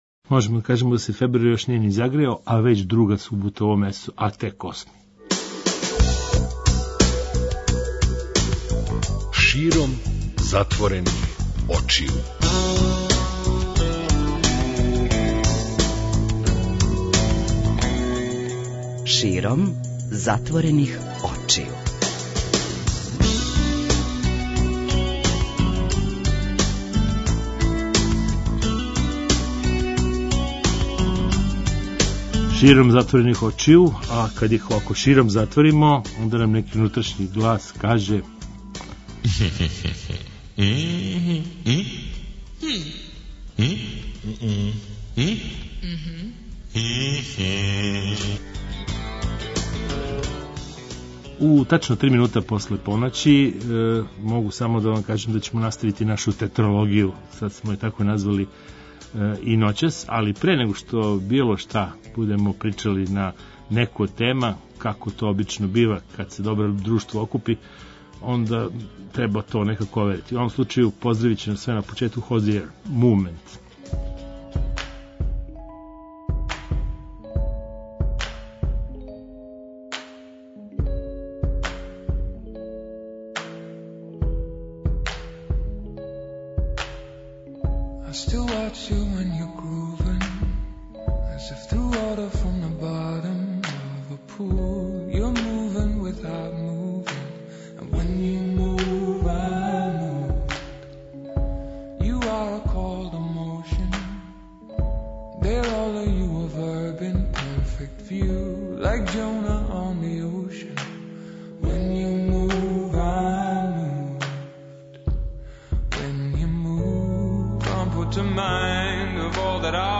Зато ћемо и ноћас да причамо о неколико тема уз непрекидан контакт са публиком.